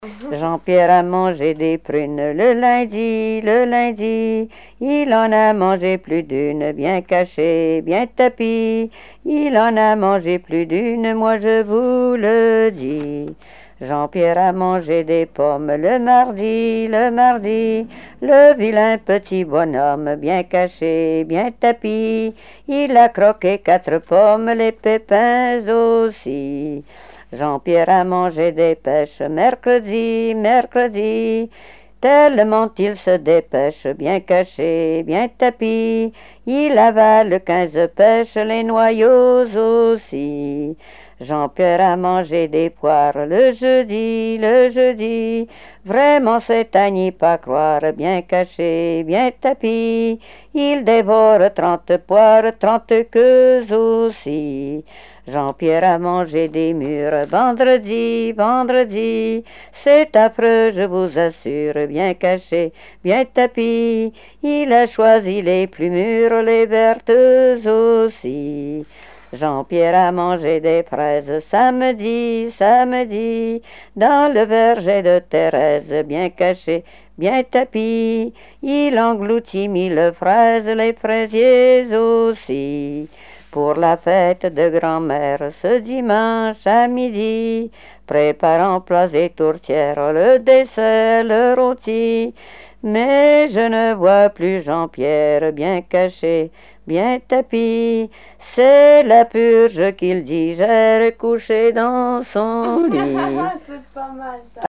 Genre énumérative
Répertoire de chansons traditionnelles et populaires
Pièce musicale inédite